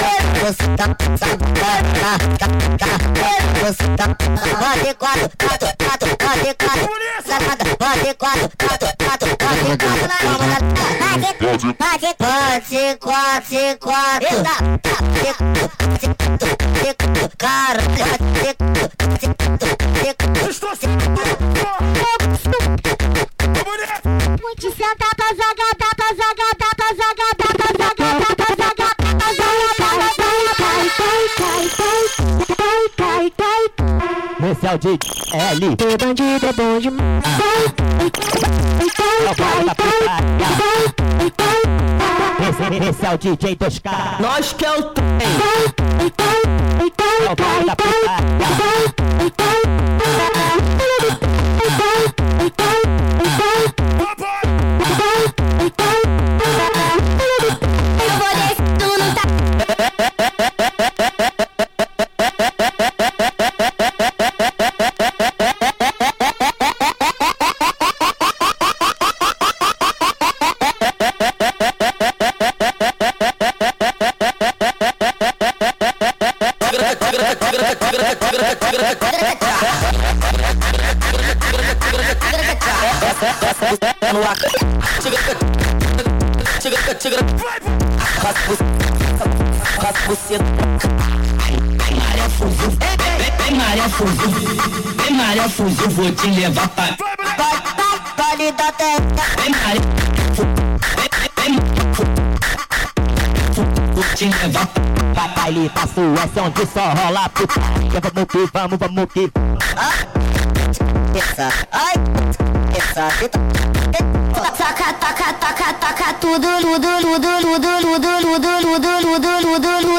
com o som mais acelerado e demente do baile funk brasileiro